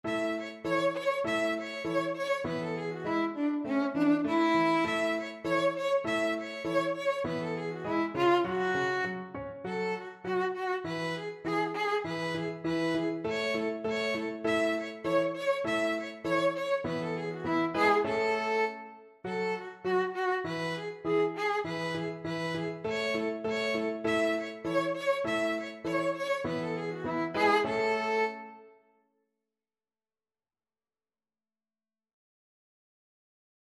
Allegretto
2/4 (View more 2/4 Music)
Db5-E6
Classical (View more Classical Violin Music)